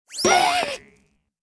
avatar_emotion_surprise.mp3